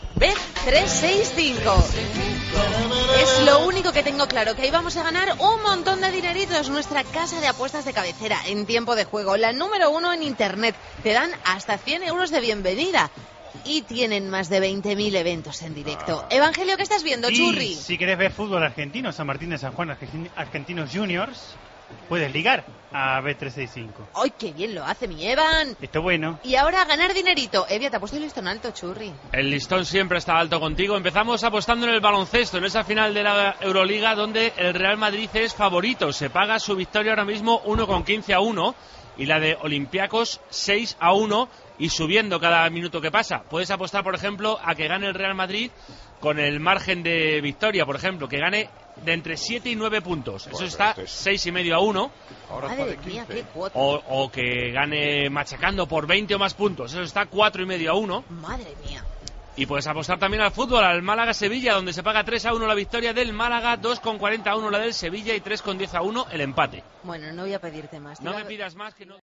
Publicitat i apostes del moment
Esportiu
FM